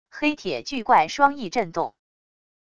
黑铁巨怪双翼振动wav音频